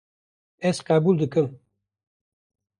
Pronounced as (IPA)
/qɛˈbuːl/